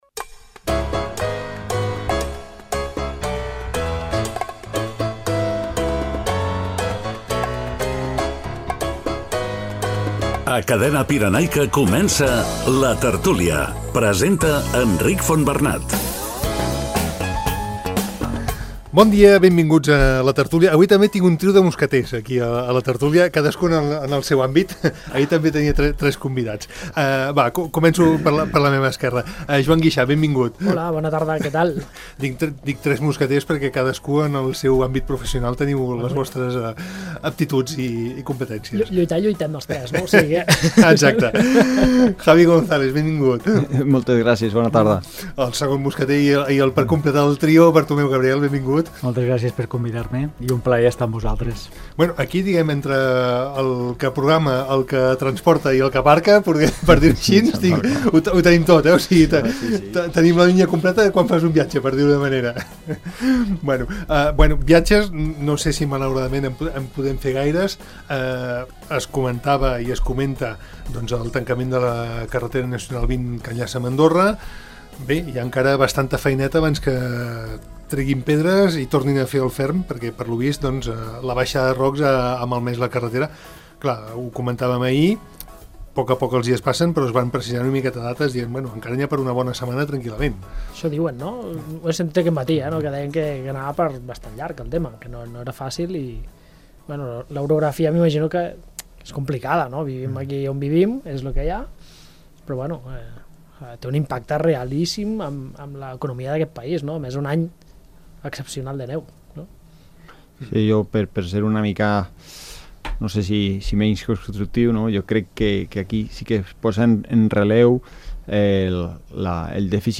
LA TERTÚLIA